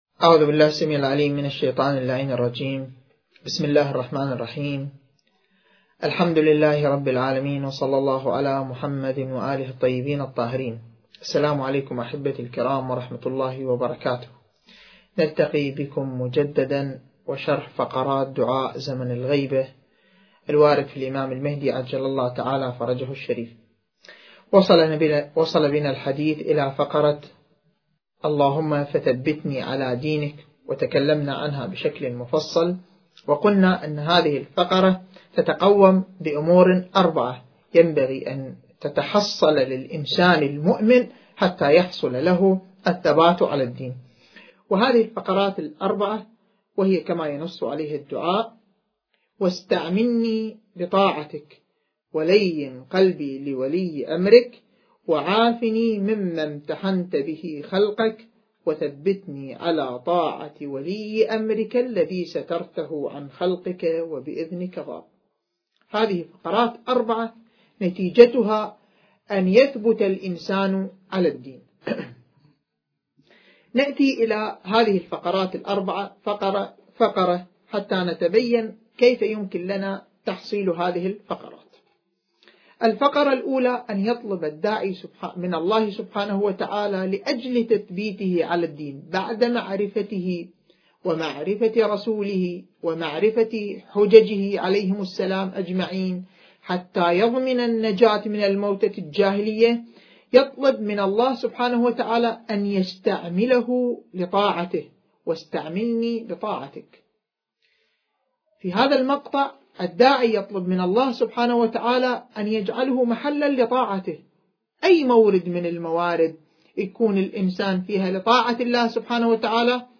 سلسلة: شرح دعاء زمن الغيبة (اللهم عرفني نفسك) (4) المكان: استوديو مركز الدراسات التخصصية في الإمام المهدي (عجّل الله فرجه) / النجف الأشرف التاريخ: ٢٠١١